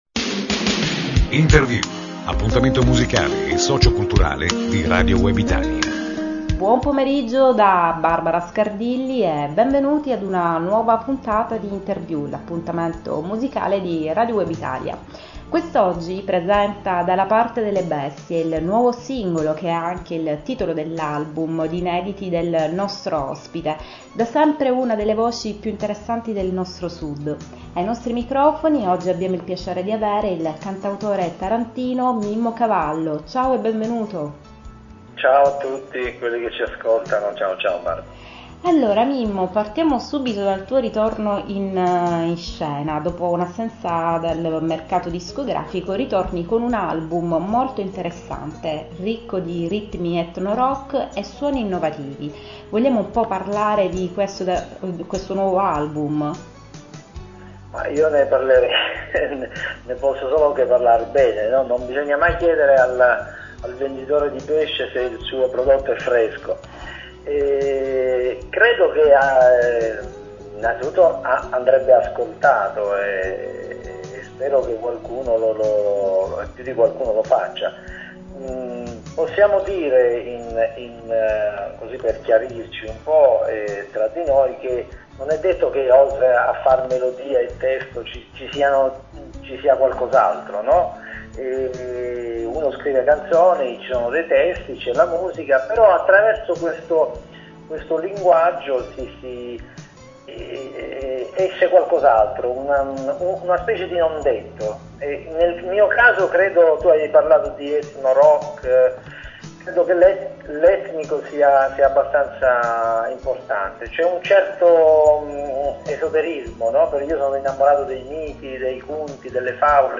Mimmo-Cavallo-intervista-2.mp3